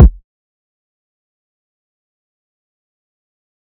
Kick (200).wav